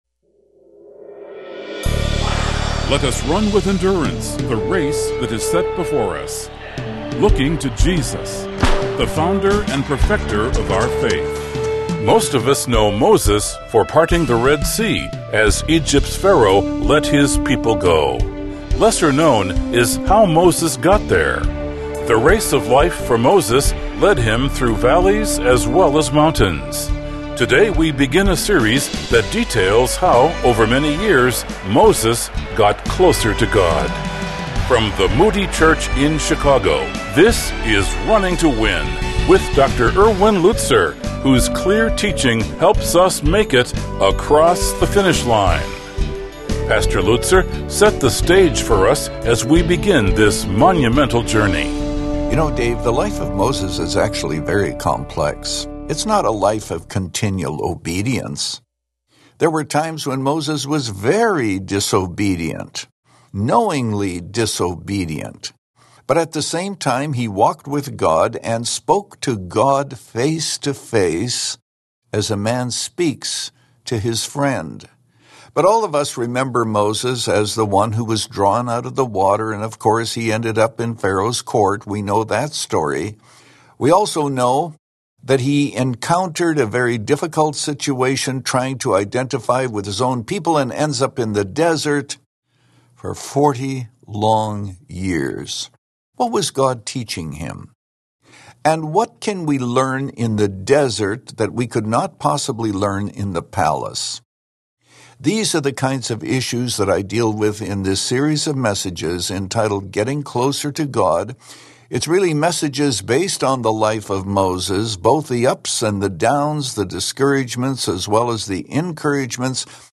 Moses' journey took him from Egypt's throne room to herding sheep in obscurity. In this message from Exodus 2